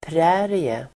Folkets service: prärie prärie substantiv (särskilt i USA), prairie [particularly in the US] Uttal: [pr'ä:rie] Böjningar: prärien, prärier Definition: torr grässlätt prairie substantiv, prärie Förklaring: torr grässlätt